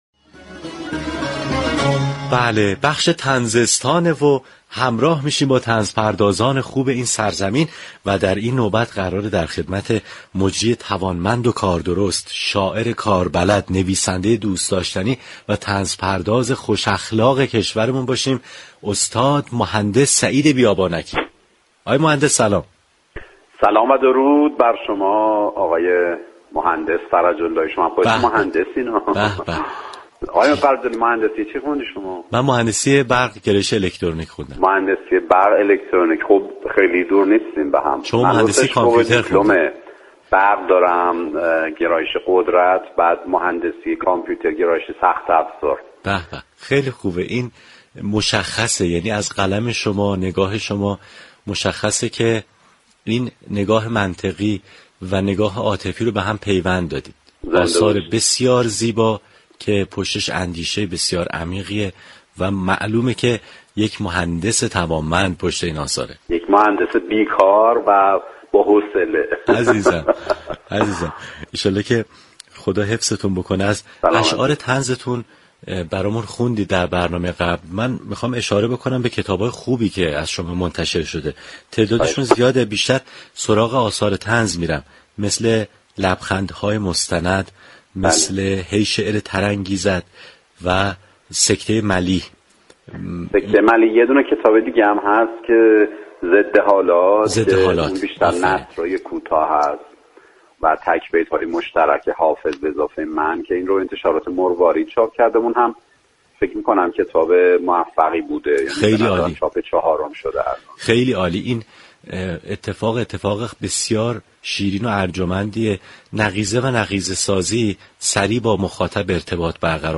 شنونده گفتگوی برنامه لیموترش با سعید بیابانكی، طنزپرداز باشید.